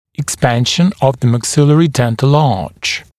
[ɪk’spænʃn əv ðə mæk’sɪlərɪ ‘dentl ɑːʧ] [ek-] [ик’спэншн ов зэ мэк’силэри ‘дэнтл а:ч] [эк-] расширение верхнего зубного ряда